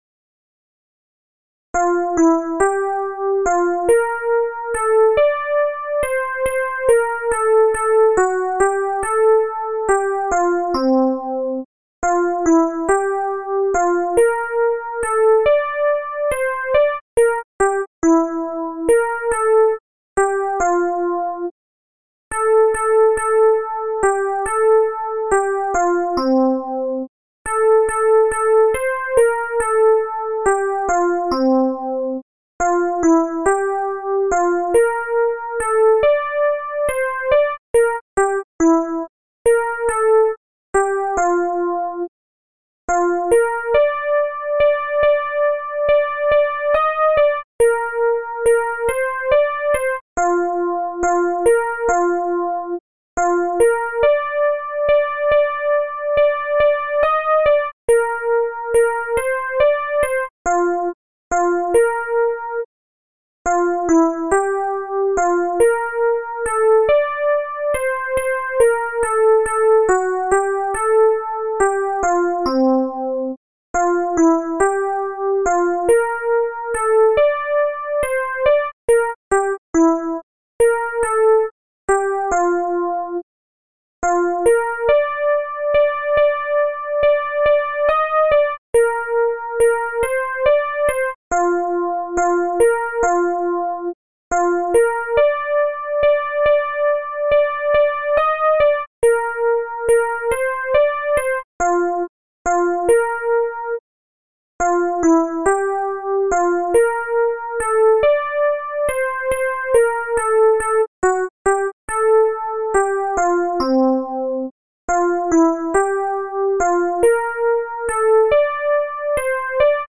Alti
barcarola_alti.MP3